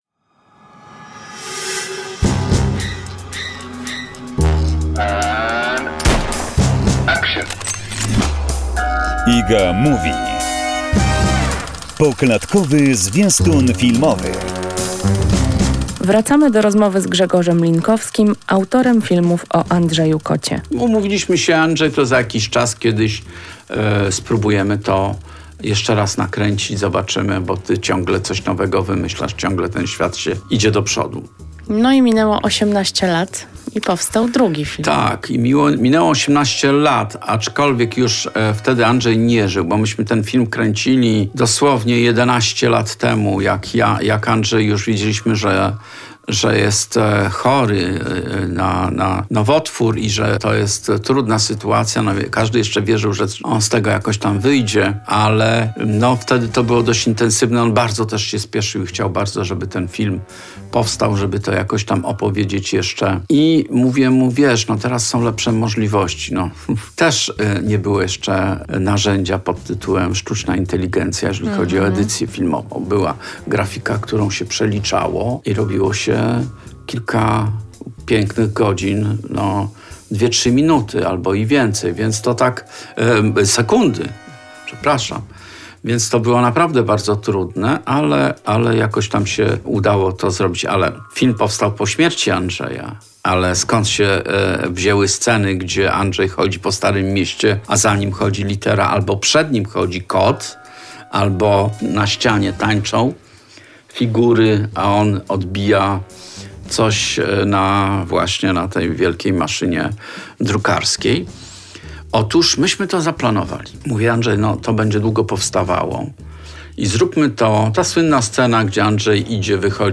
kolejną część rozmowy